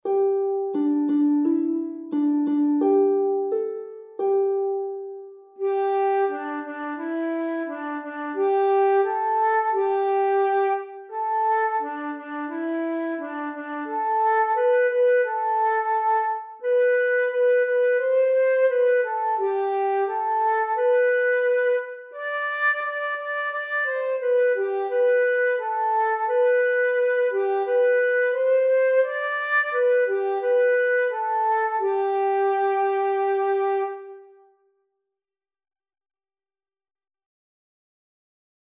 Spelers komen op, zingend